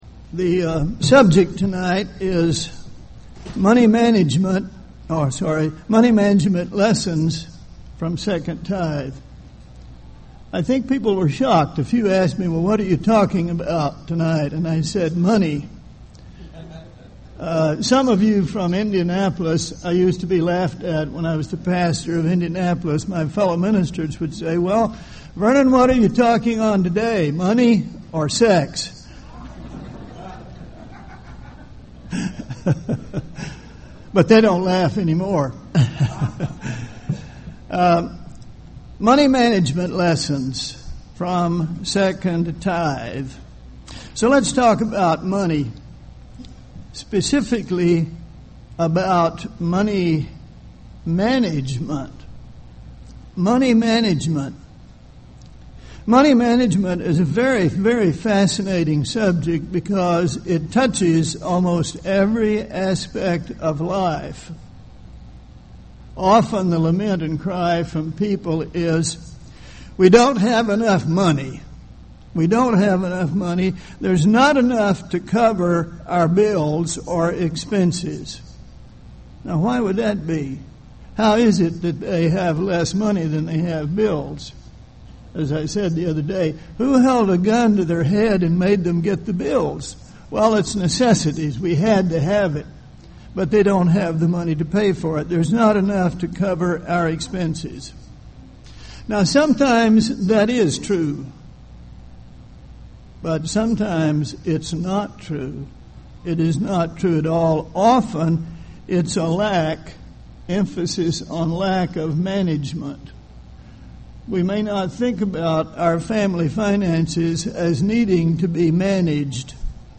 This sermon was given at the Jekyll Island, Georgia 2011 Feast site.